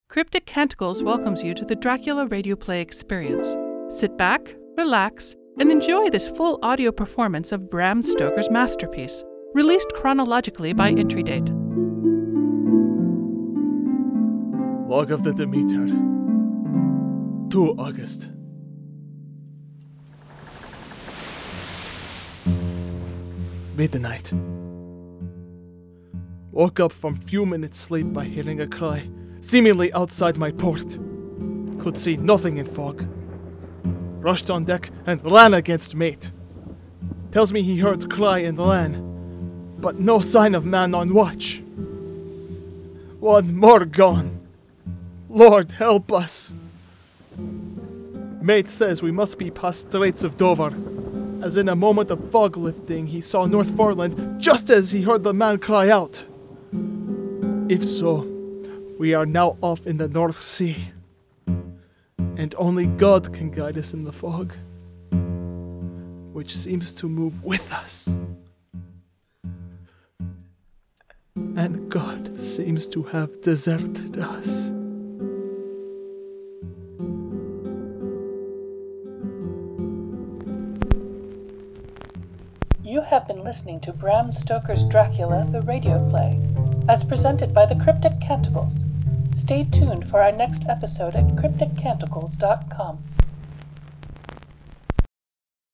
This is a rebroadcast of the original 2017 work.